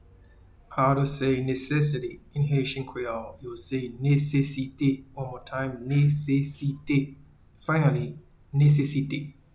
Pronunciation:
Necessity-in-Haitian-Creole-Nesesite.mp3